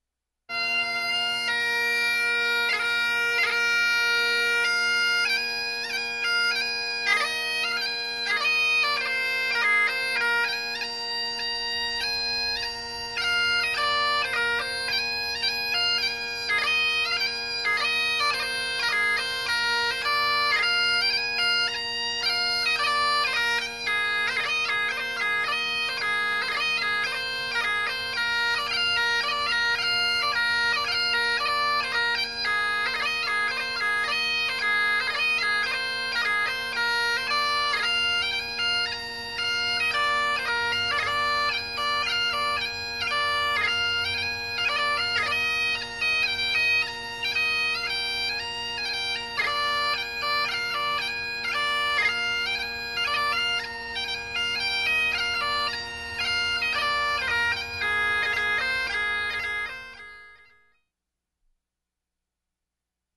Мелодия для волынки